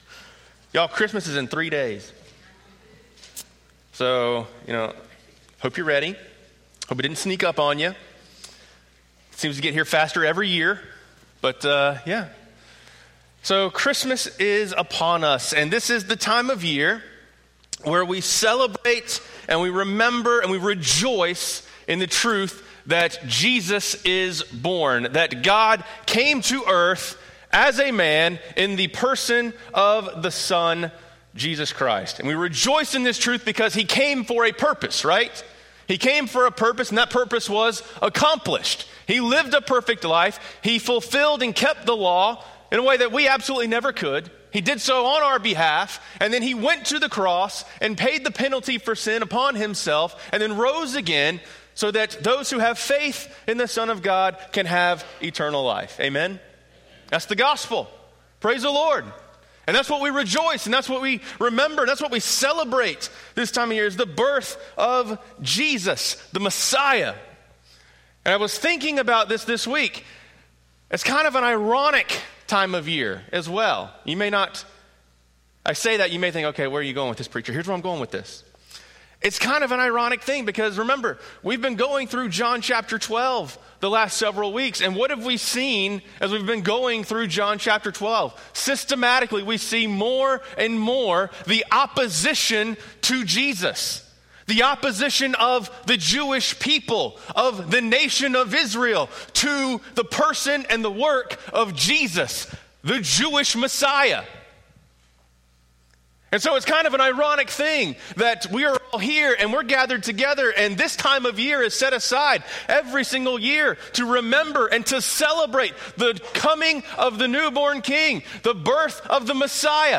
Sermons «